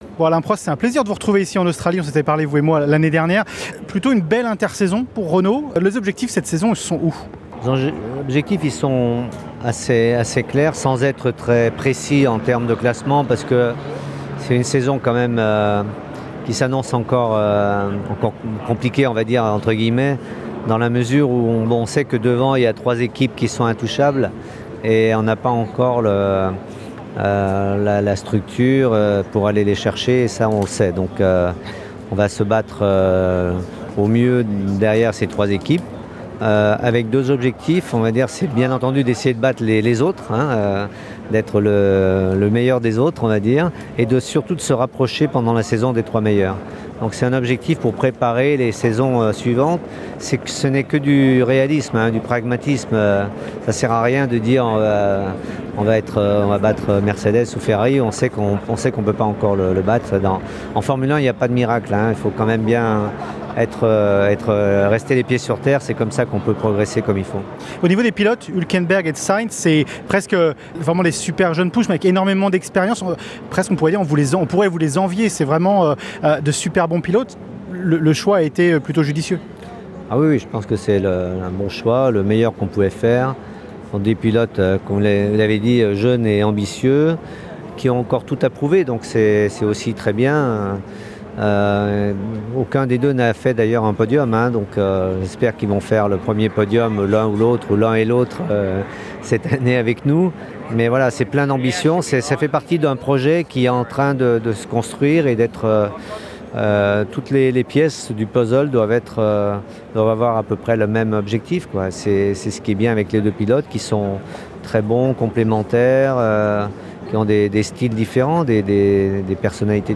Alain Prost en Australie - Notre interview Exclusive